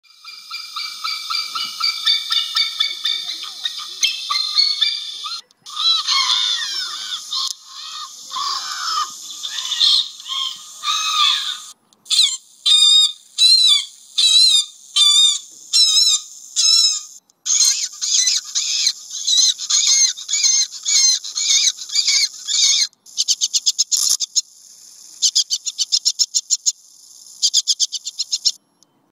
Ещё один вариант: голубь, чайка, воробей и скворец